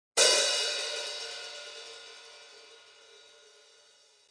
Sabian 14" HHX Legacy Hi-Hat Cymbals
Very responsive pairing delivers clean, crisp stick articulation and solid pedal 'chick', with warm, tonal color.